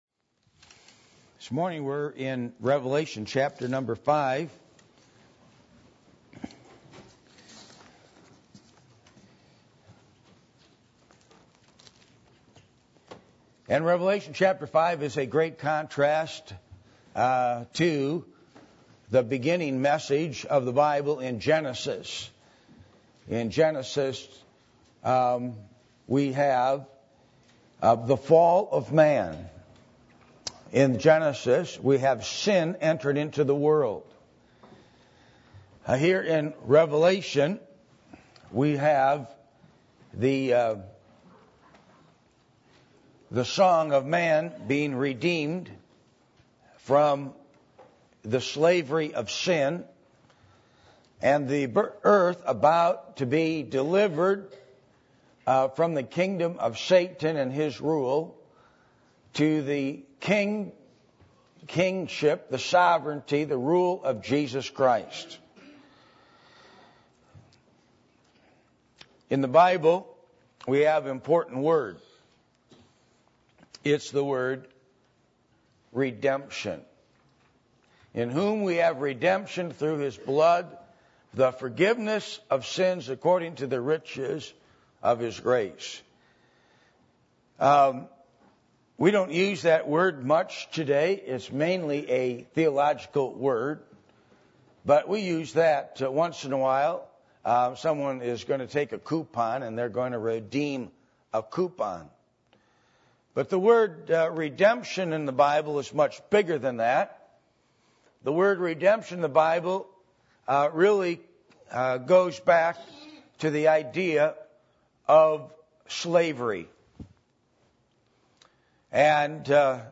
Passage: Revelation 5:1-14 Service Type: Sunday Morning %todo_render% « How To Interpret The Bible